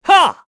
Esker-Vox_Attack3_kr.wav